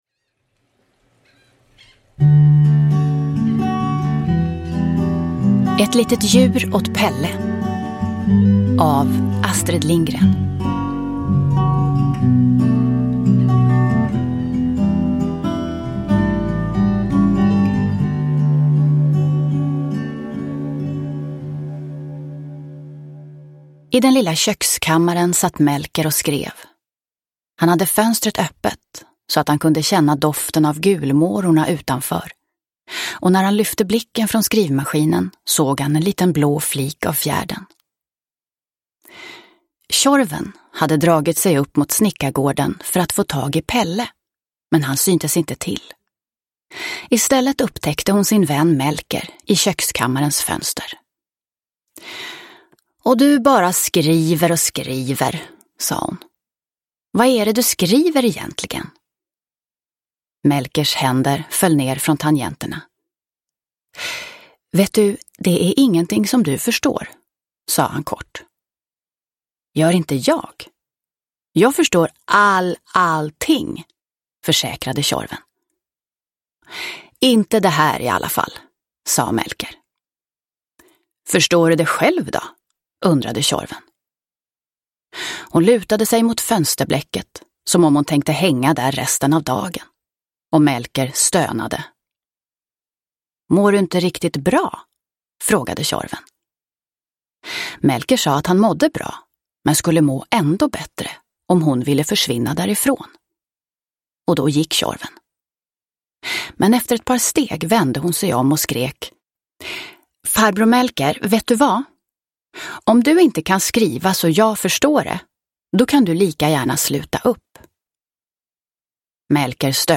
Ett litet djur åt Pelle – Ljudbok – Laddas ner